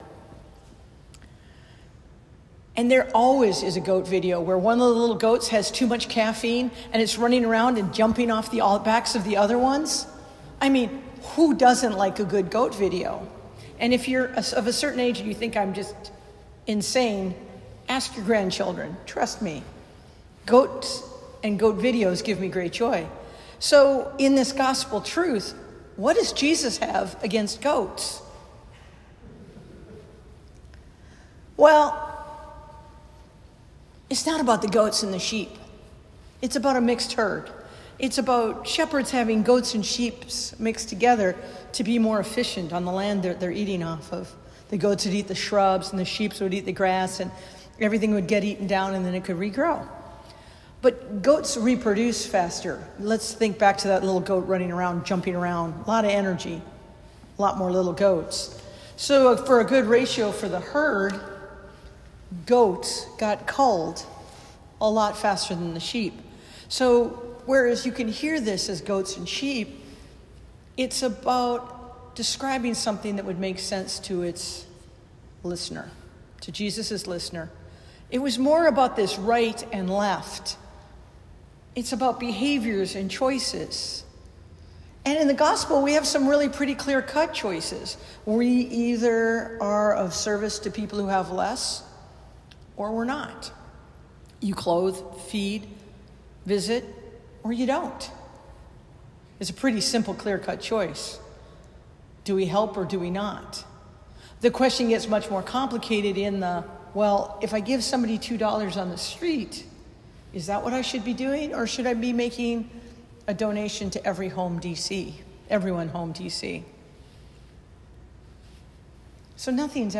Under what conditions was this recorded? Christ the King Sunday